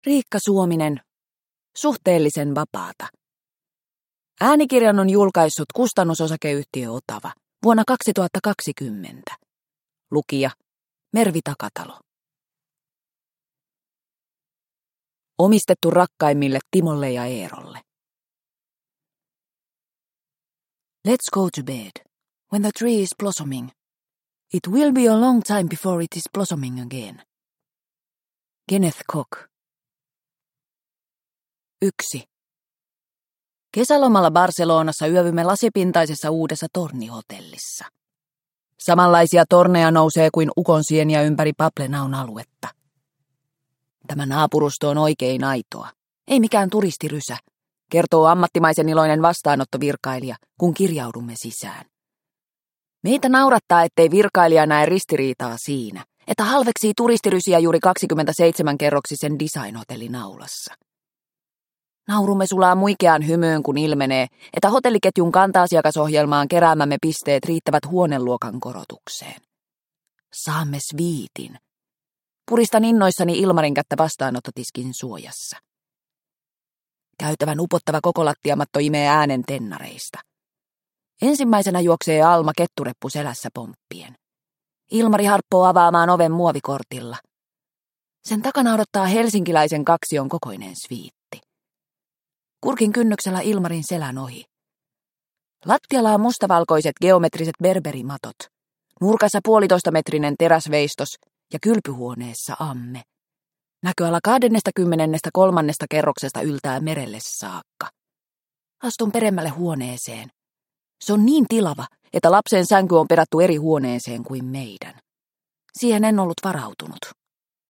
Suhteellisen vapaata – Ljudbok – Laddas ner